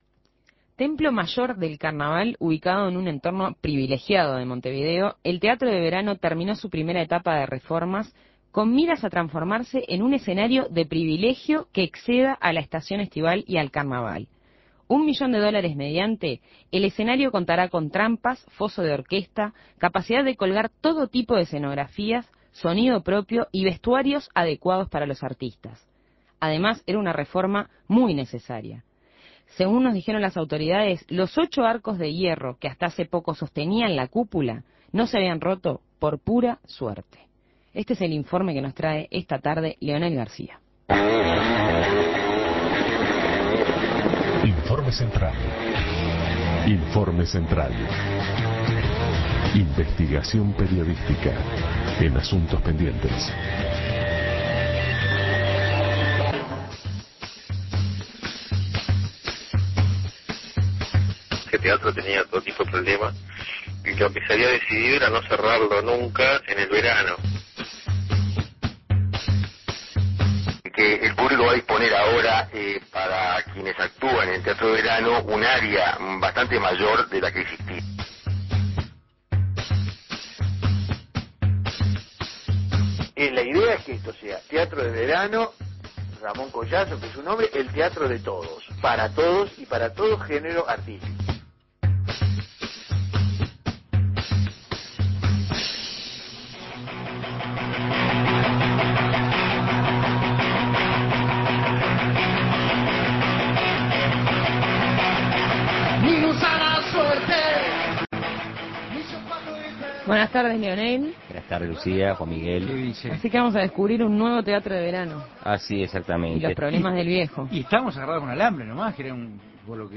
Un espacio que aspira a ser un escenario de privilegio que exceda a la temporada estival y al carnaval. Informe